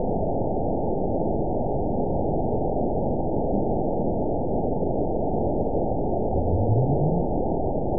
event 922582 date 02/05/25 time 06:31:50 GMT (4 months, 2 weeks ago) score 9.68 location TSS-AB02 detected by nrw target species NRW annotations +NRW Spectrogram: Frequency (kHz) vs. Time (s) audio not available .wav